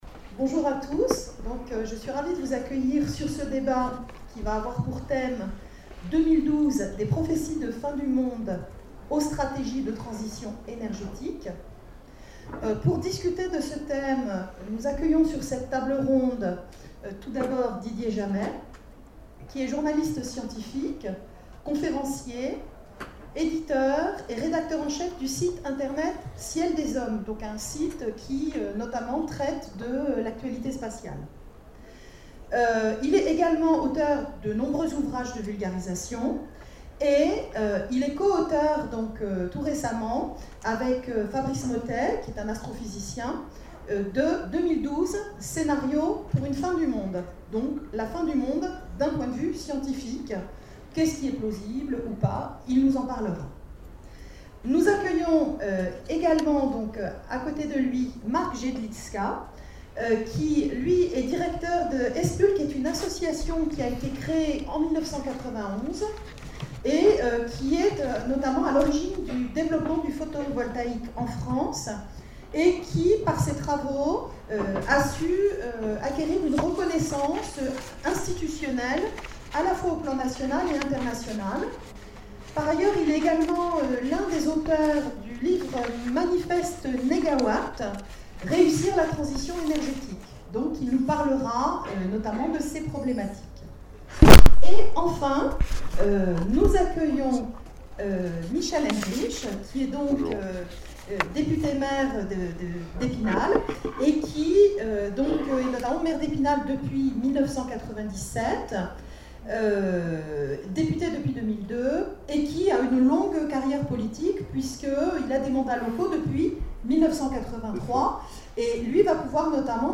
Imaginales 2012 : Conférence 2012, des prophéties de fin du monde...